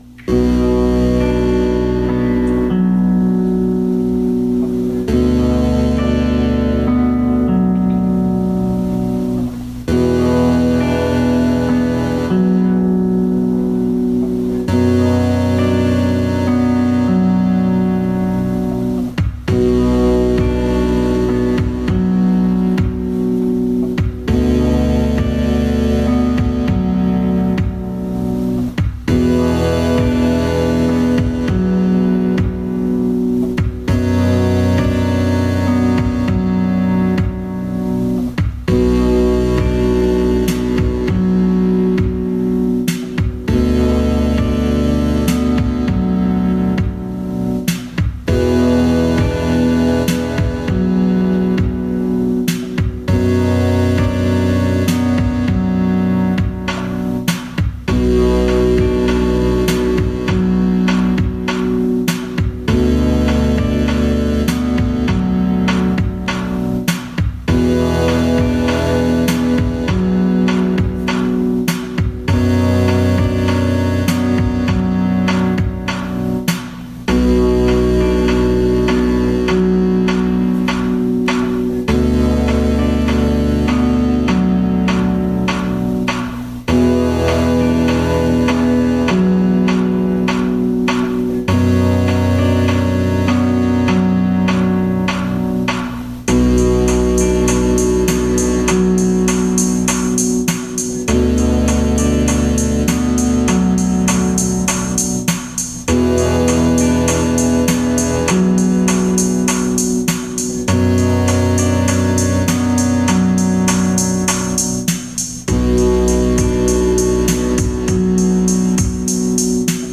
Files: MP3 audio with the RC3 driving the synths with a simple guitar loop: Download the loop PCB Gerbers: PIC Code: